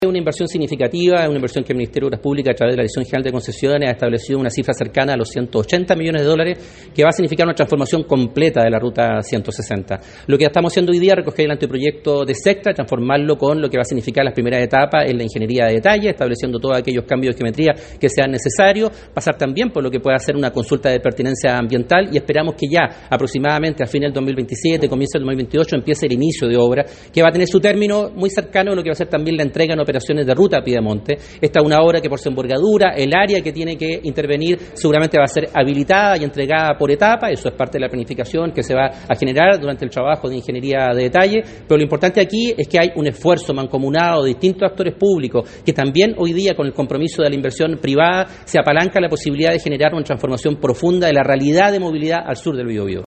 Hugo Cautivo, seremi de Obras Públicas y Transportes, expresó que “se siguen derribando mitos en la conectividad del Biobio (…) con esta nueva concesión vamos a generar un nuevo estándar en materia de movilidad”.